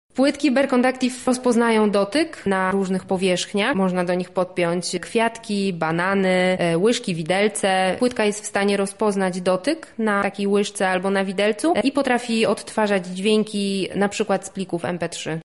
W miniony weekend na ulicy grodzkiej odbyły się medialabowe warsztaty z programów audio.